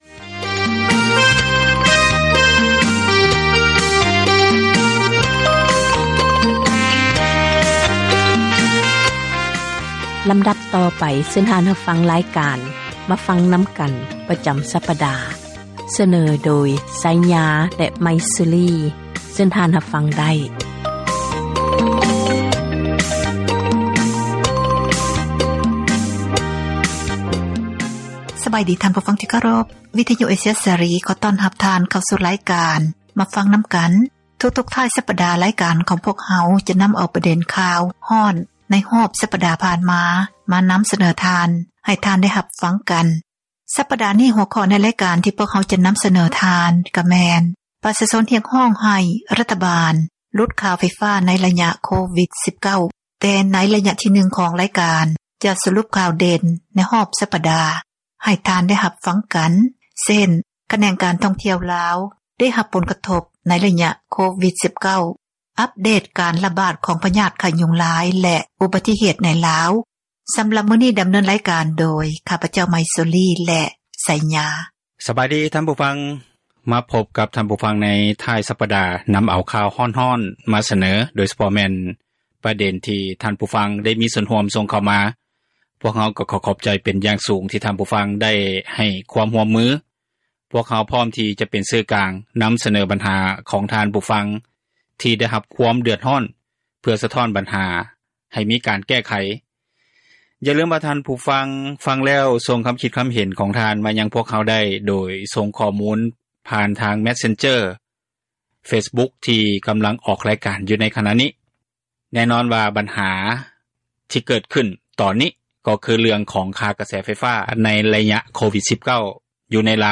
"ມາຟັງນຳກັນ" ແມ່ນຣາຍການສົນທະນາ ບັນຫາສັງຄົມ ທີ່ຕ້ອງການ ພາກສ່ວນກ່ຽວຂ້ອງ ເອົາໃຈໃສ່ແກ້ໄຂ, ອອກອາກາດ ທຸກໆວັນອາທິດ ເວລາ 6:00 ແລງ ແລະ ເຊົ້າວັນຈັນ ເວລາ 7:00